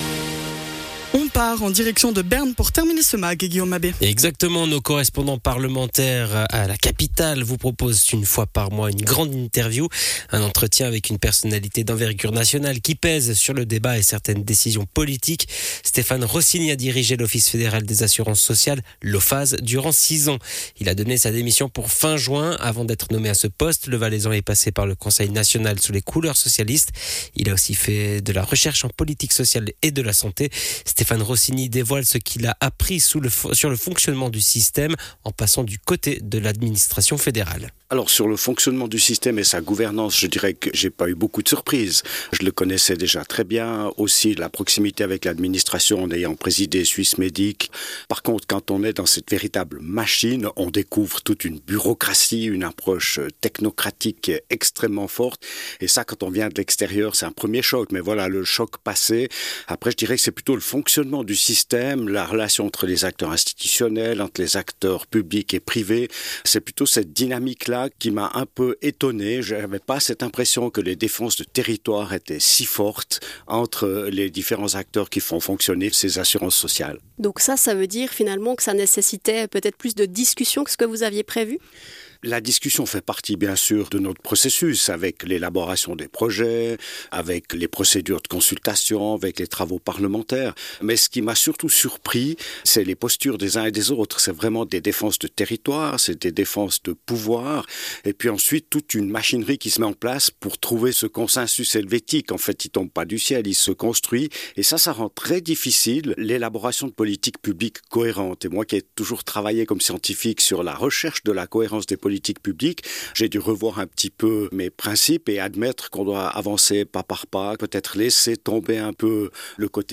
ITW: Véronique Diab-Vuadens sur les mesures concernant l'accès à Taney
Intervenant(e) : Véronique Diab-Vuadens - Présidente de la commune de Vouvry